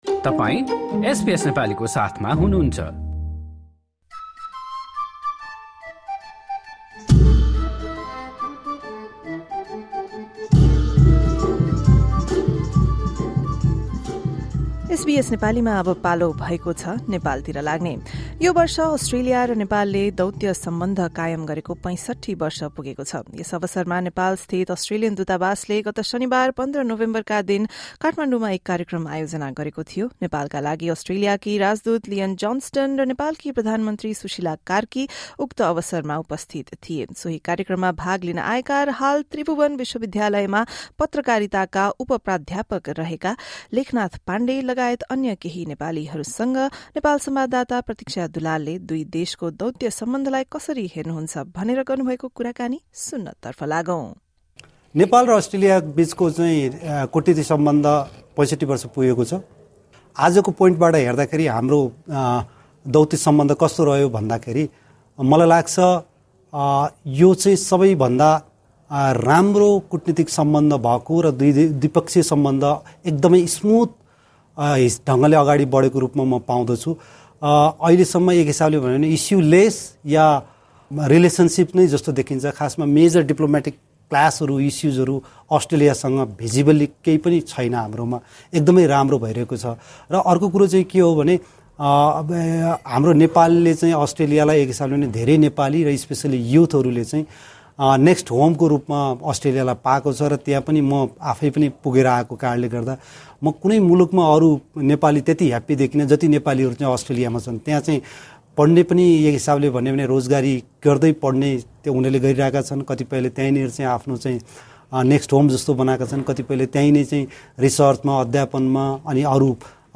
This year marks the 65th anniversary of diplomatic relations between Australia and Nepal. To mark the occasion, the Australian Embassy in Nepal hosted an event in Kathmandu on Saturday, 15 November.